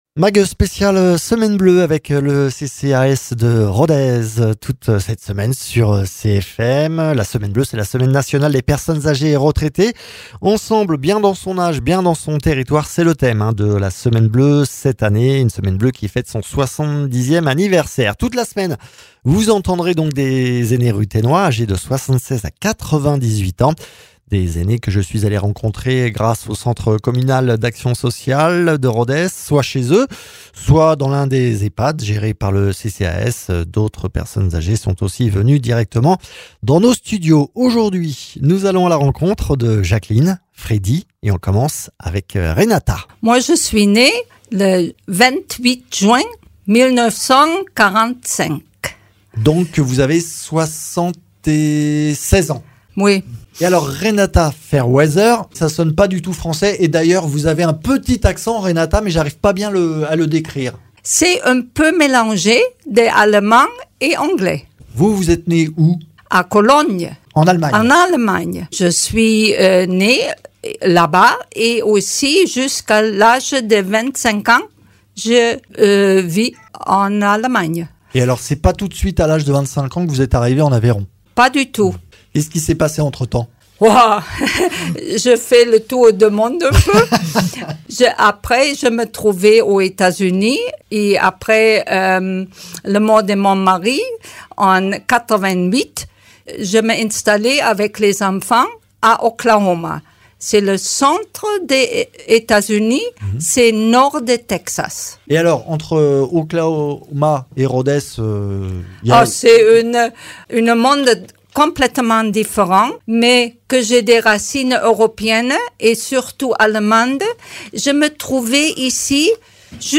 Dans le cadre de la semaine bleue, semaine nationale des personnes agées et retraités et en partenariat avec le CCAS de Rodez, des séniors ruthénois reviennent sur leur vie à Rodez et en Aveyron.